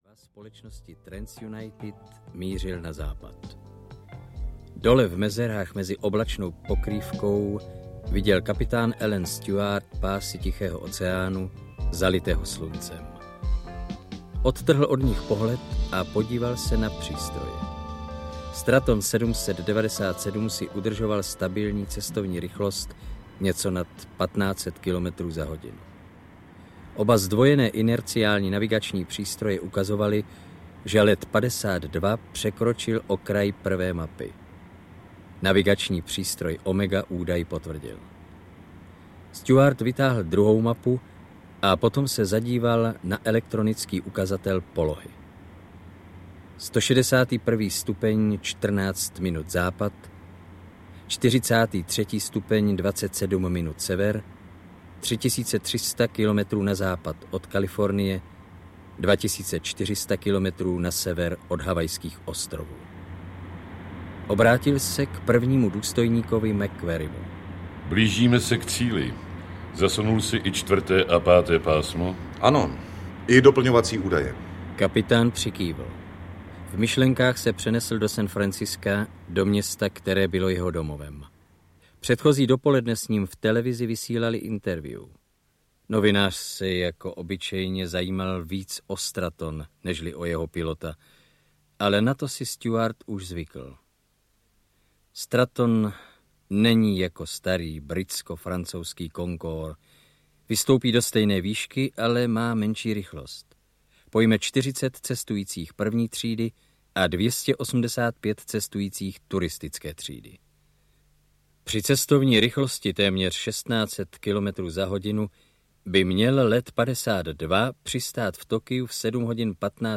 Mayday audiokniha
Ukázka z knihy